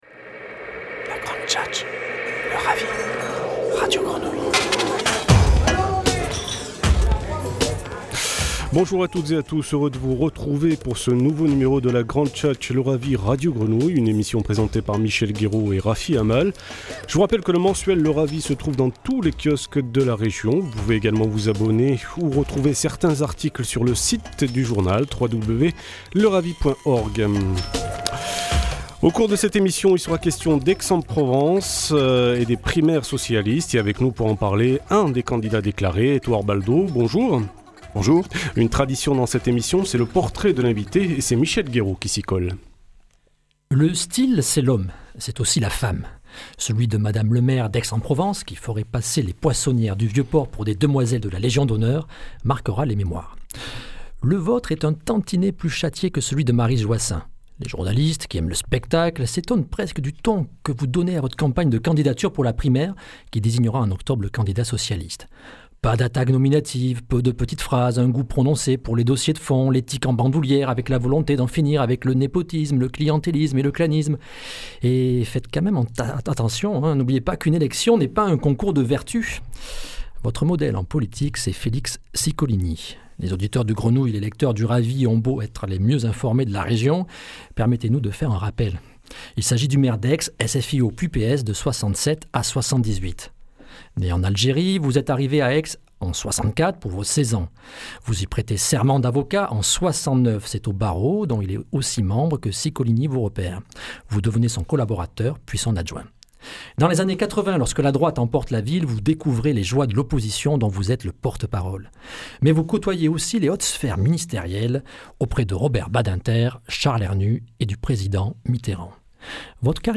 Entretien radio en partenariat avec Radio Grenouille